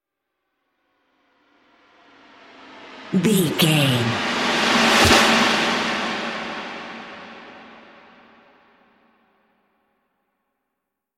E♭
drums
bass guitar
Sports Rock
hard rock
lead guitar
aggressive
energetic
intense
nu metal
alternative metal